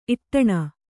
♪ iṭṭaṇa